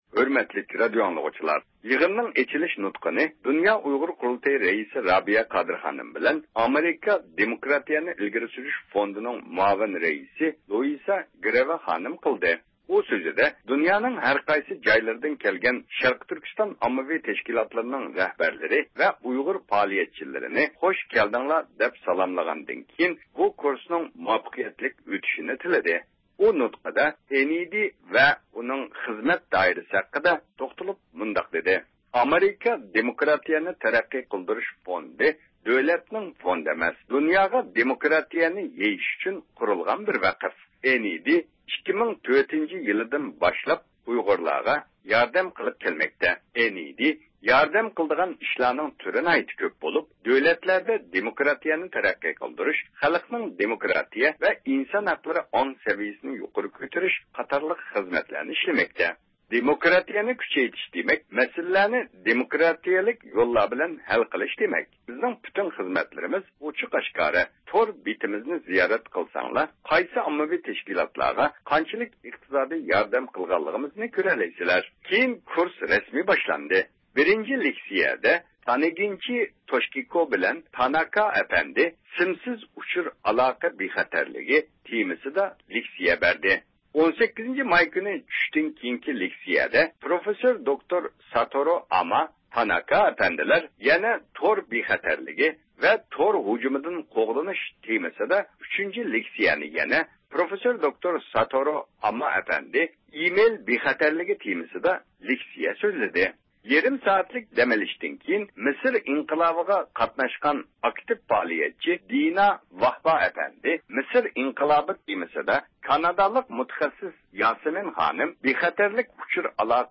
بىز بۇ كۇرس ھەققىدە تېخىمۇ تەپسىلىي مەلۇمات ئېلىش ئۈچۈن بۇ ئىش پىلانىنىڭ مۇدىرى، دۇنيا ئۇيغۇر قۇرۇلتىيىنىڭ ئىجرائىيە كومىتېتى مۇدىرى دولقۇن ئەيساغا مىكروفونىمىزنى ئۇزاتتۇق.